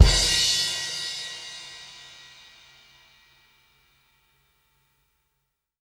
crash 2.wav